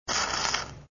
weld4.wav